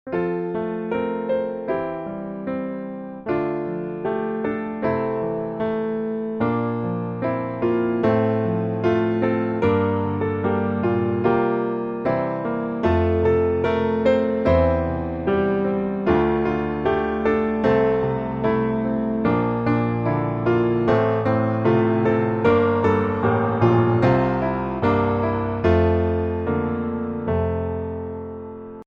F Majeur